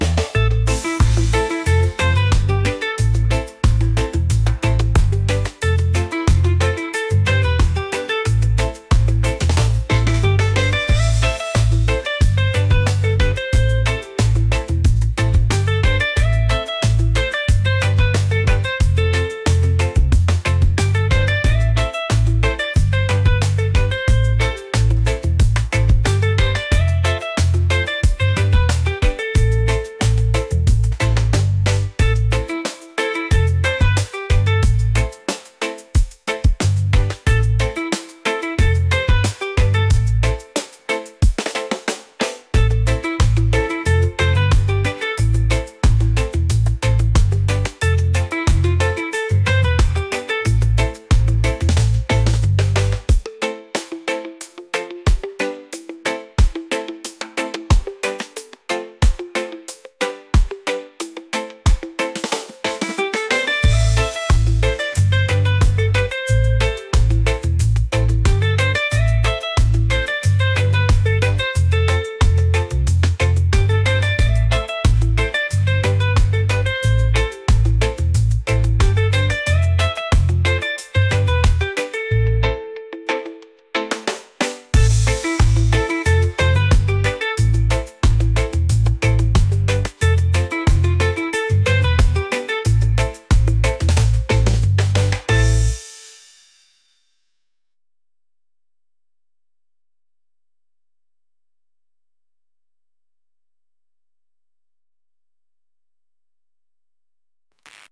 reggae | upbeat | rhythmic